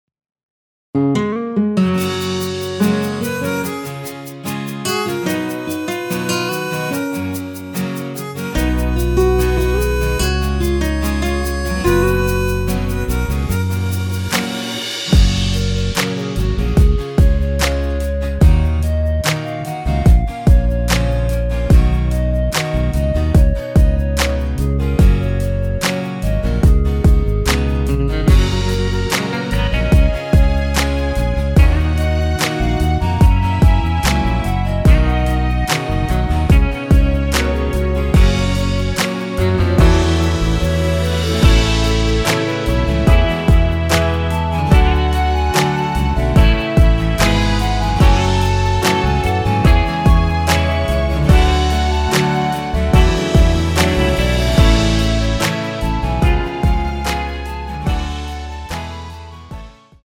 원키에서(-3)내린 멜로디 포힘된 MR입니다.(미리듣기 확인)
F#
앞부분30초, 뒷부분30초씩 편집해서 올려 드리고 있습니다.
중간에 음이 끈어지고 다시 나오는 이유는